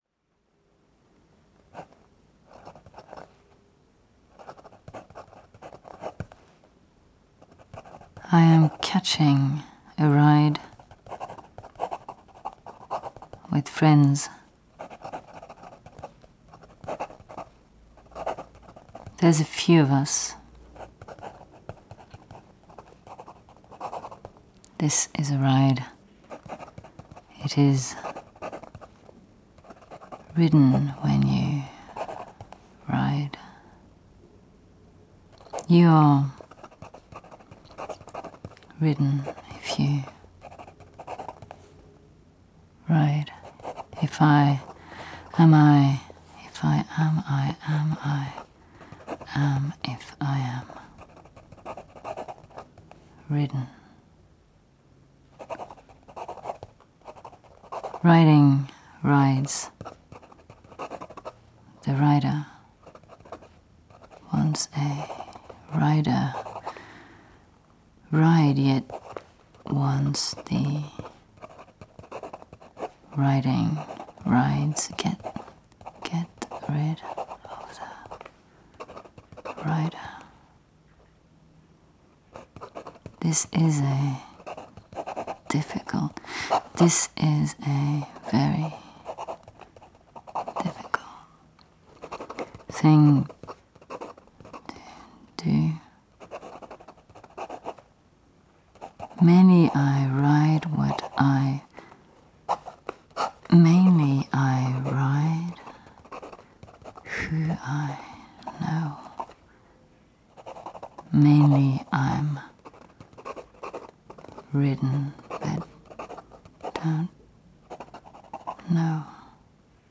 Gestures of writing as audible tracing. The piece itself deals with a verbal slippage between ‚writing‘ and ‚riding‘.
I use the oral, sounded phonemes ‚t‘ and ‚d‘ as my basic measure to signify cultural accent. They shift between the British dry ‚t‘ of the writer and the American wet ‚t‘ of the wrider.